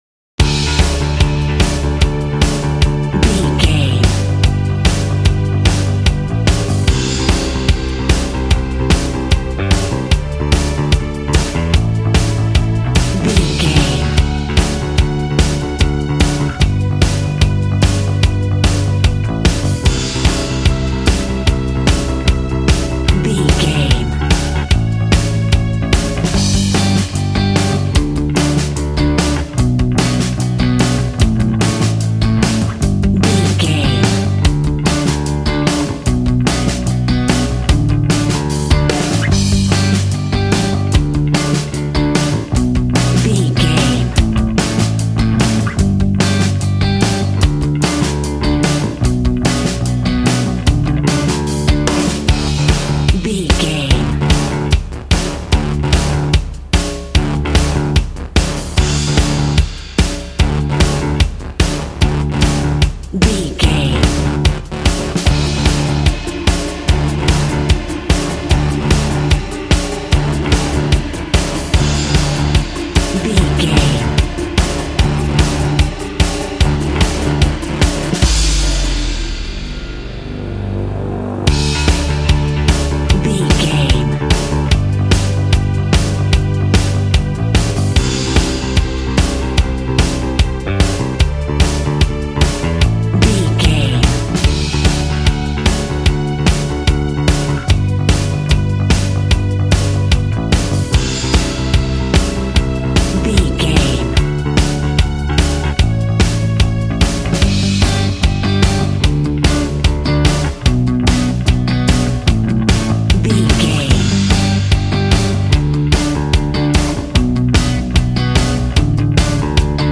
Ionian/Major
groovy
powerful
organ
drums
bass guitar
electric guitar
piano